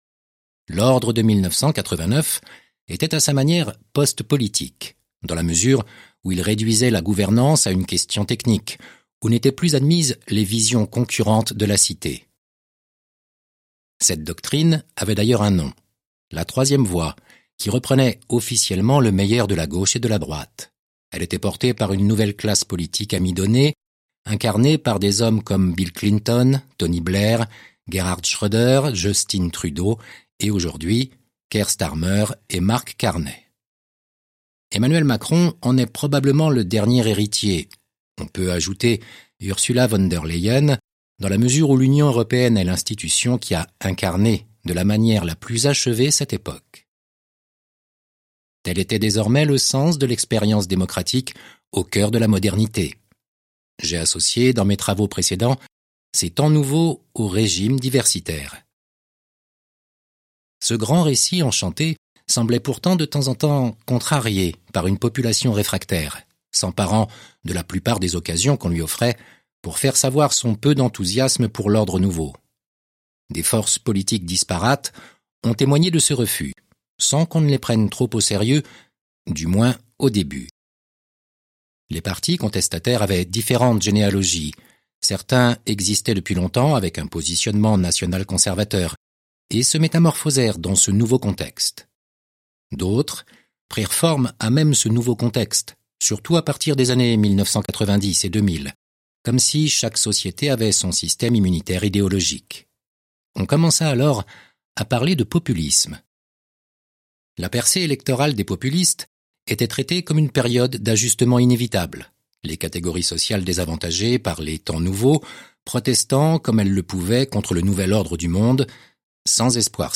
Livre audio : Les Deux Occidents de Mathieu Bock-Côté